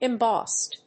発音記号
• / ɪˈmbɔst(米国英語)
• / ɪˈmbɔ:st(英国英語)